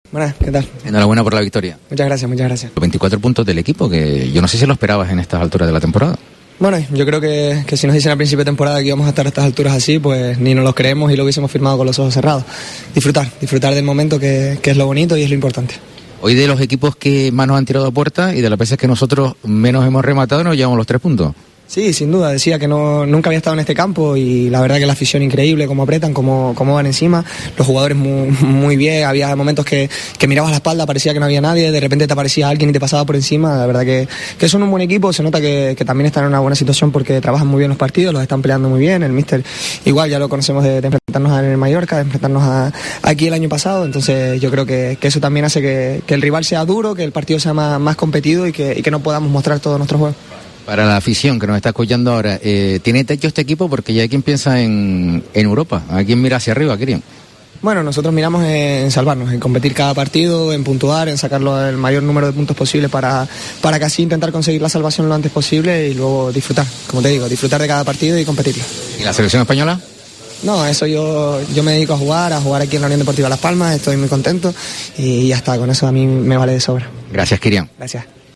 El jugador de la UD Las Palmas analiza el buen momento que vive el equipo
Después del triunfo en Mendizorra (0-1) ante el Deportivo Alavés, Kirian habló en la Cadena COPE de la situación actual del equipo.